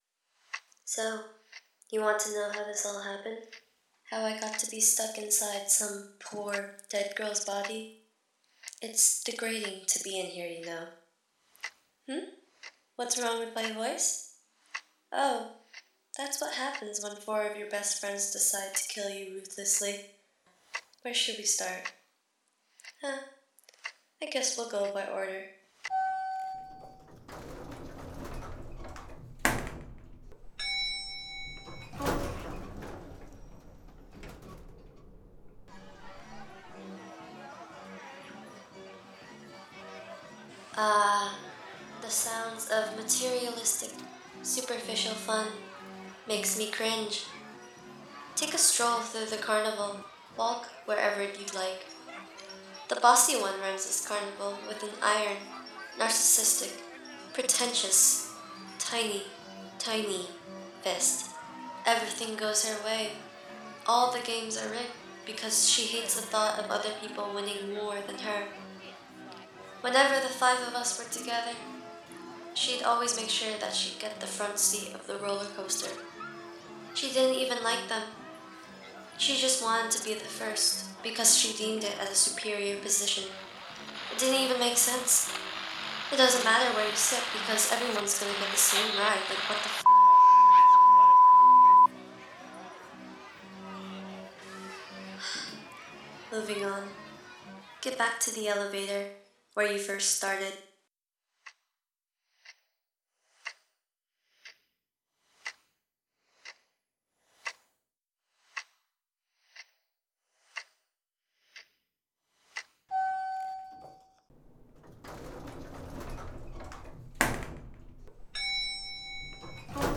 Audio Walk